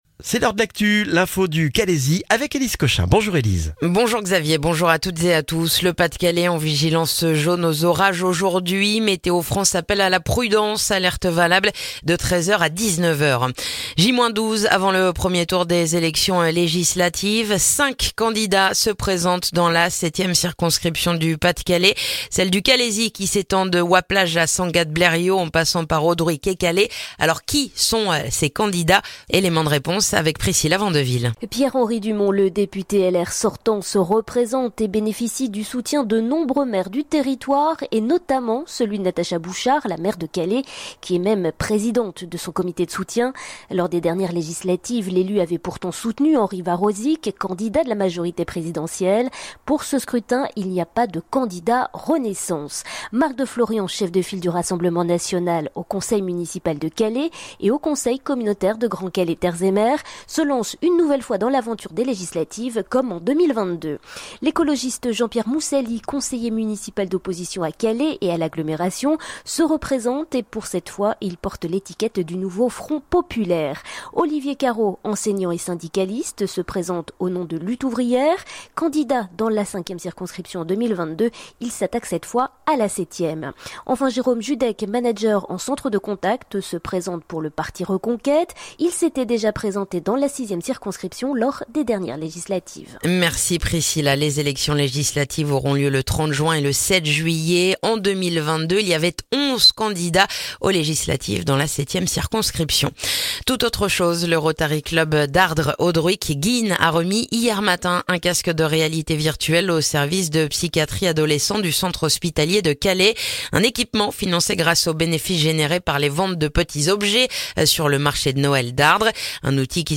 Le journal du mardi 18 juin dans le calaisis